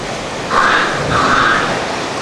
Audio files for the following article: Aerial Vocalizations by Wild and Rehabilitating Mediterranean Monk Seals (Monachus monachus) in Greece
Adult Snort
adultsnort.wav